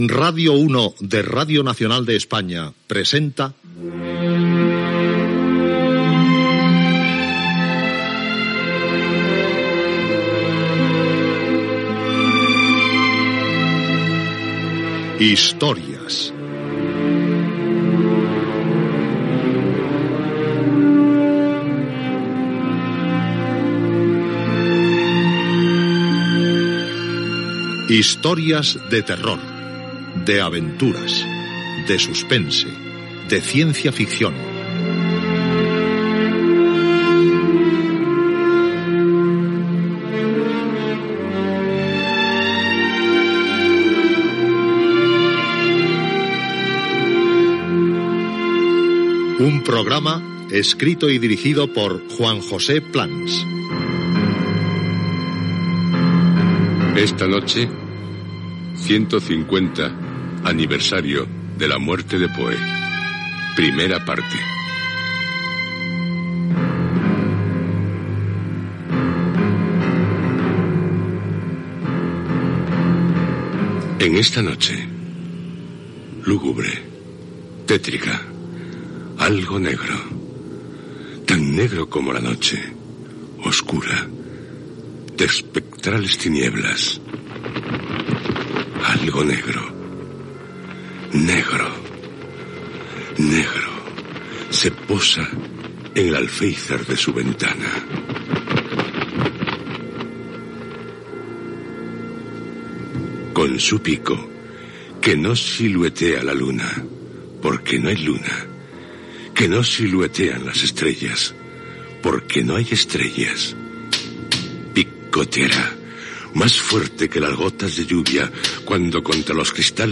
Careta del programa i inici del primer espai dedicat als 150 anys de la mort d'Edgar Alan Poe, amb dades biogràfiques i escrits de l'autor.
Ficció